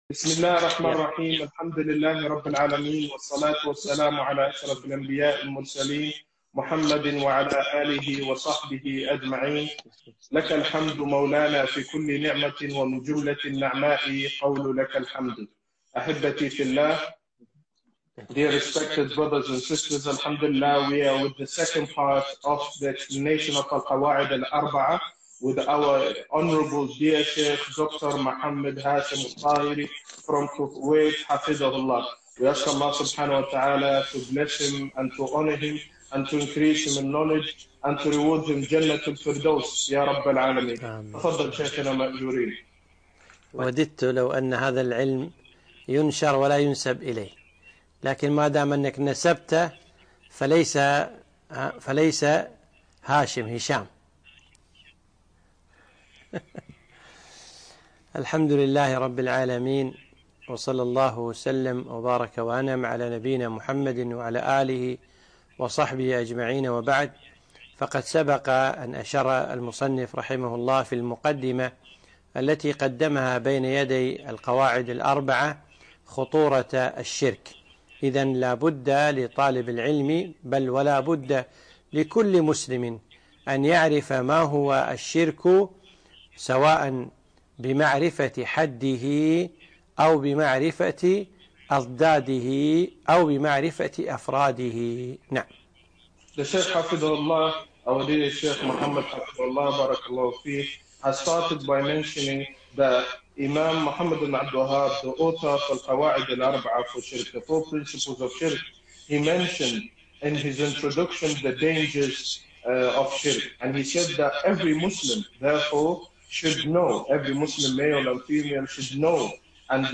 (2) شرح القواعد الأربع مع الترجمة الإنجليزية - المجلس الثاني